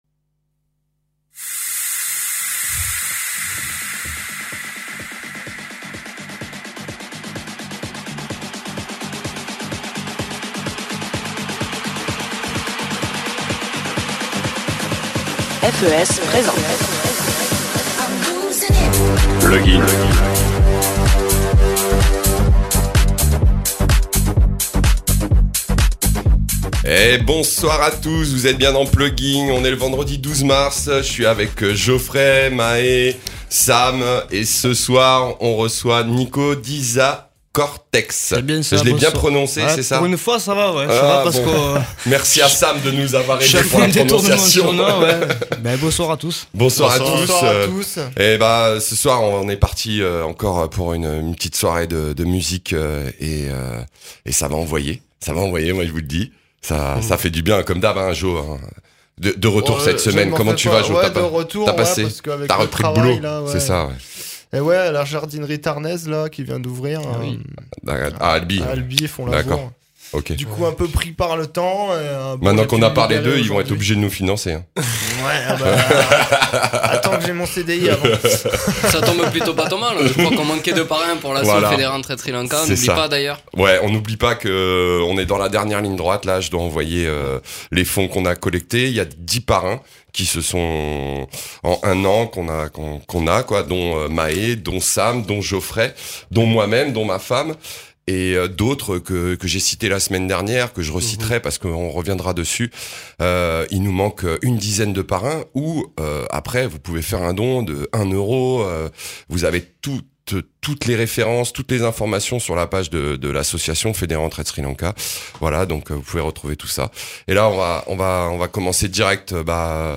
pour un mix old school/tribe/elctrotrance/dnb/htek/hcore.